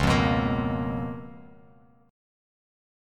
C#13 Chord
Listen to C#13 strummed